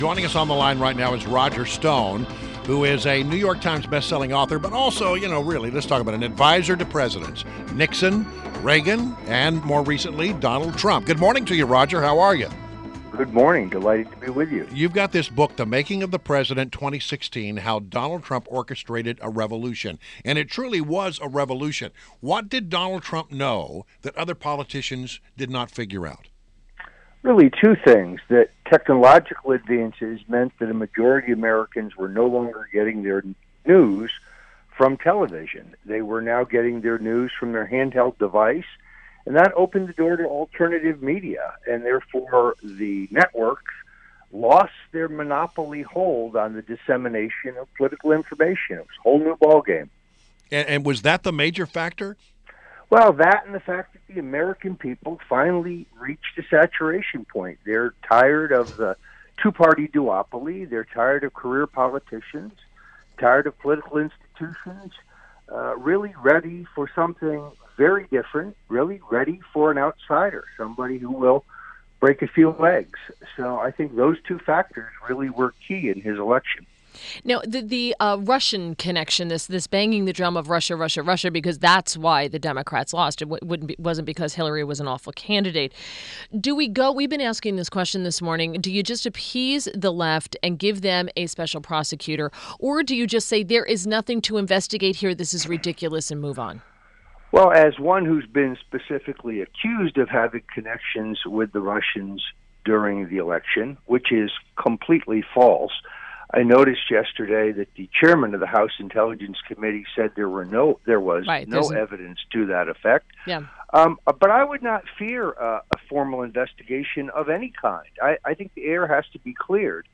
WMAL Interview - ROGER STONE - 02.28.17